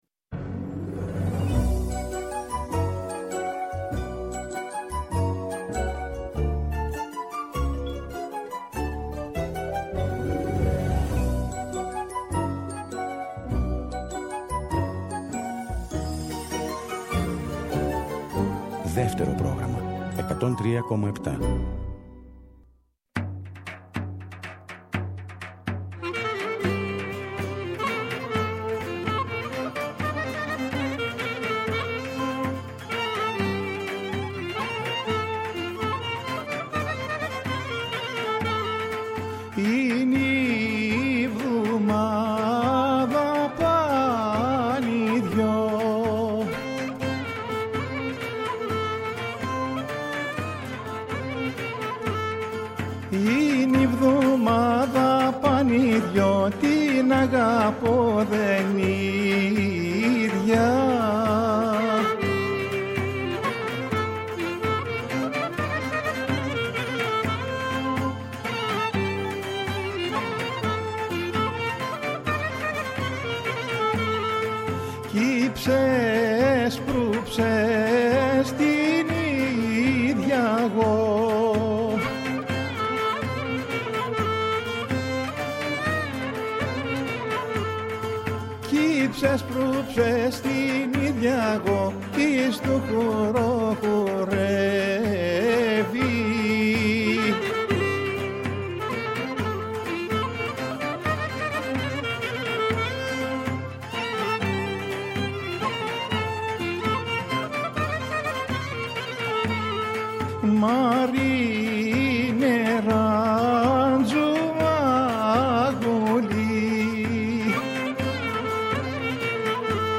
Η παραδοσιακή μας μουσική, το δημοτικό τραγούδι, οι αγαπημένοι μας δημιουργοί από το παρελθόν, αλλά και η νεότερη δραστήρια γενιά των μουσικών, συνθέτουν ένα ωριαίο μουσικό παραδοσιακό γαϊτανάκι.